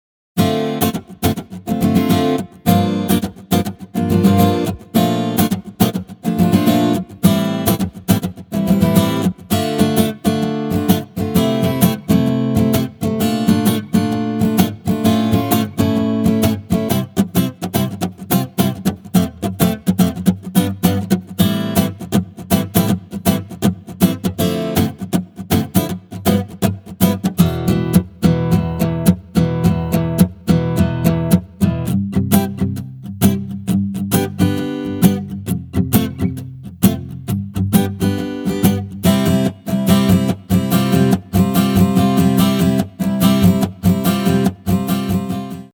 True Legato 2.0 AcousticGuitar 2.1 uses the new True Legato 2.0. a very realistic legato with slide ups and downs, Hammer Ons, Pull Offs, natural vibrato on each legato and slide noises.
Strum patch now works perfectly in sync with DAWs, perfect Timing , all patterns revised, now they sound natural.
For example,when you play a chord, the strum plays all the notes with delay between the notes to simulate the passage of the pick on the strings.
AcousticGuitar_Kontakt_Patterns.mp3